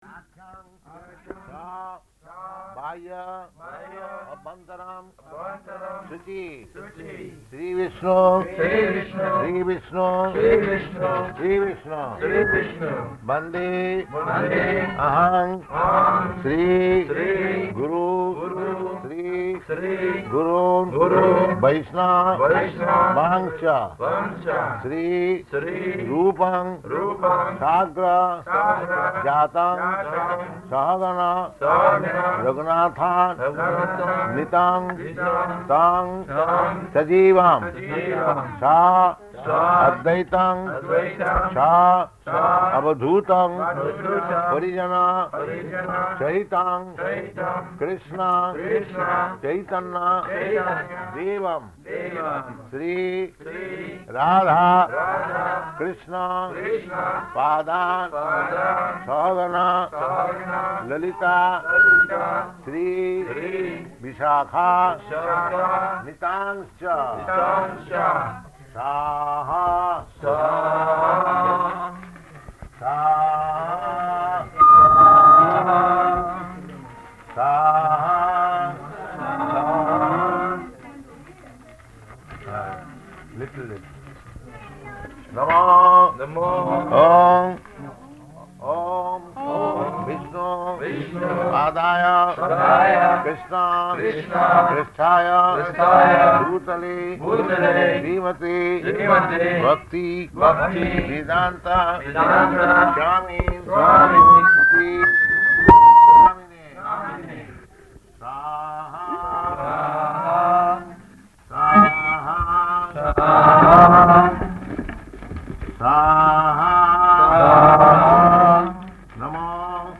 Type: Lectures and Addresses
Location: Auckland